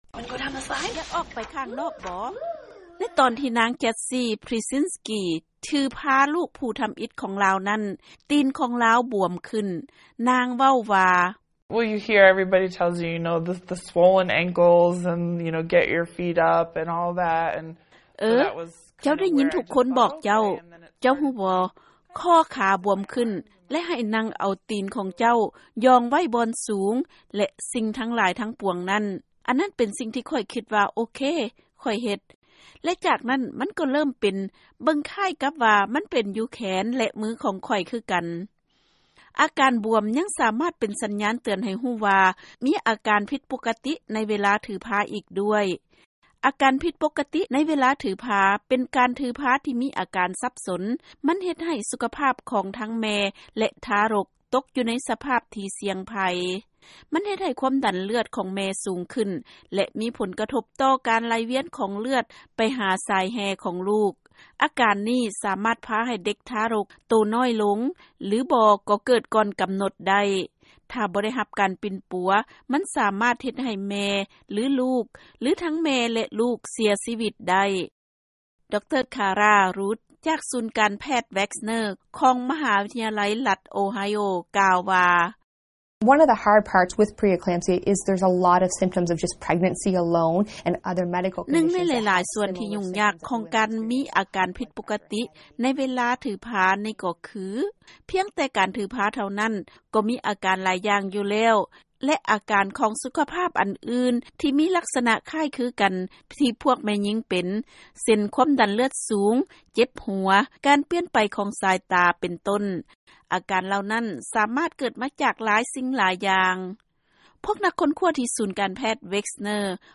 ເຊີນຟັງລາຍງານການກວດແບບໃໝ່ຫາອາການຜິດປົກກະຕິໃນເວລາຖືພາ